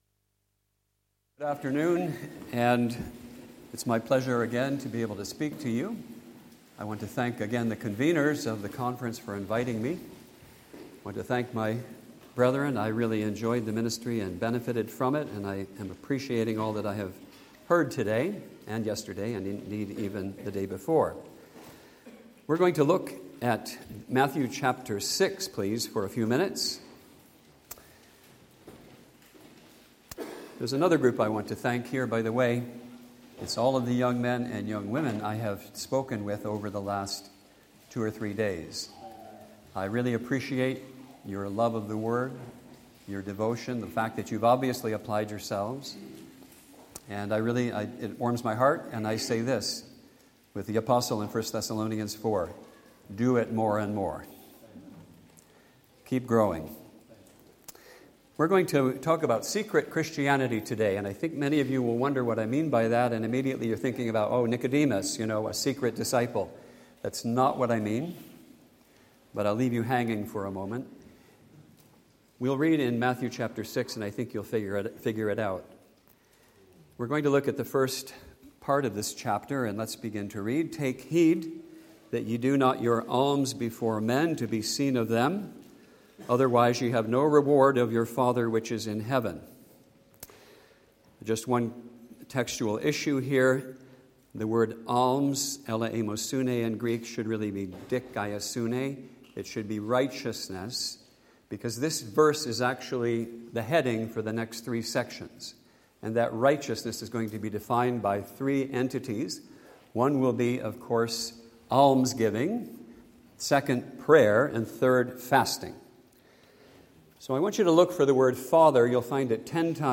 EASTER CONF 2025